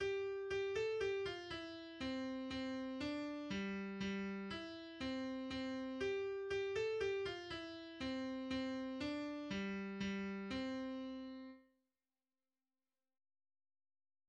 — австрийская народная песня.